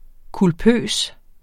Udtale [ kulˈpøˀs ]